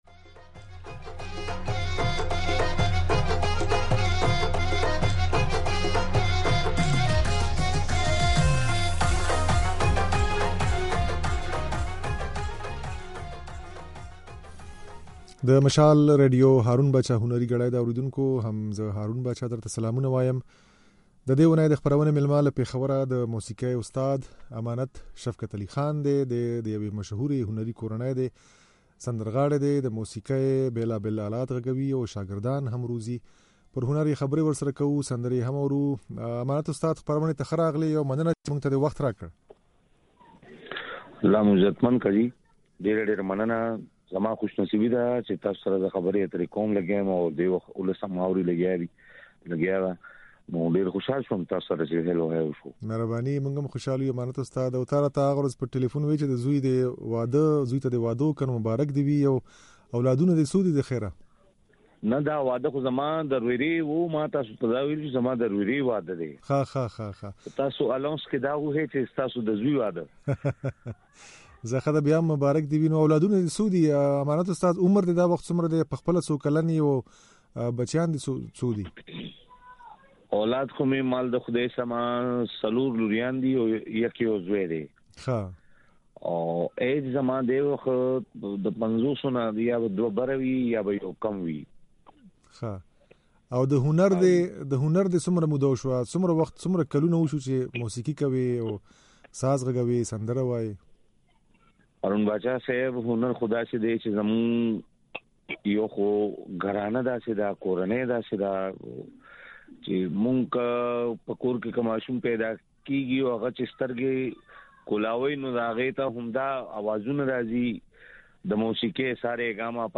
د نوموړي د ژوند او هنر په اړه بشپړه خپرونه واورئ چې پکې نغمې هم لرو.